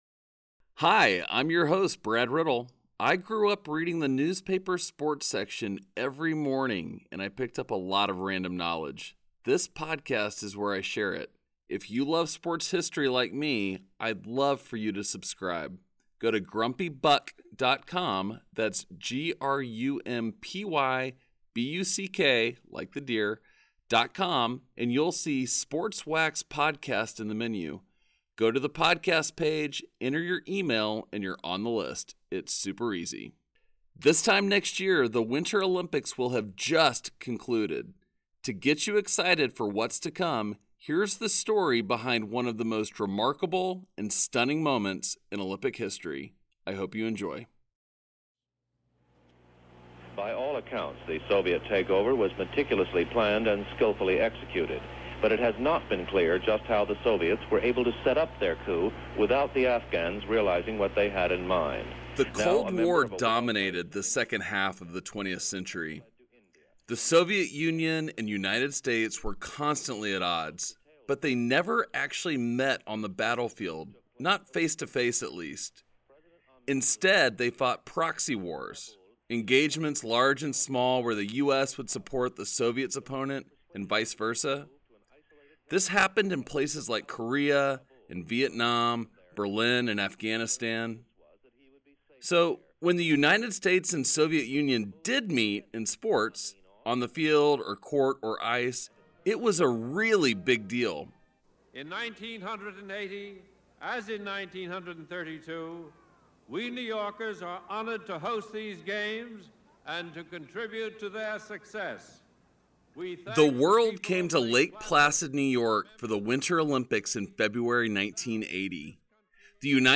Sports Wax is a weekly documentary that explores sports stories from yesteryear — memories of players, coaches, and teams that takes us back in time.